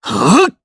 Shakmeh-Vox_Attack3_jp.wav